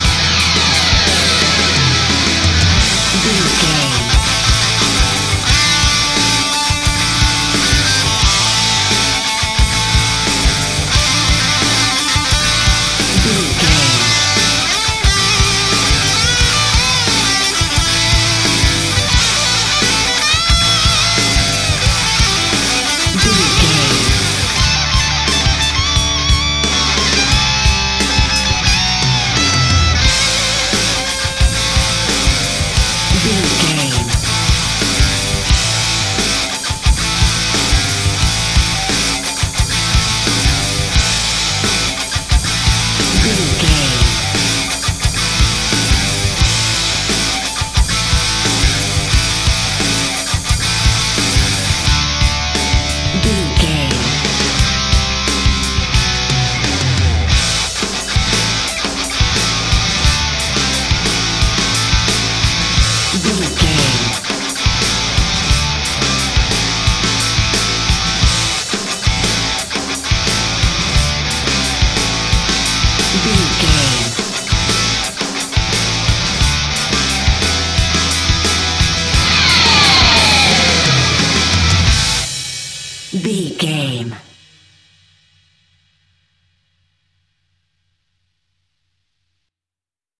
Ionian/Major
drums
electric guitar
bass guitar
pop rock
hard rock
lead guitar
aggressive
energetic
intense
powerful
nu metal
alternative metal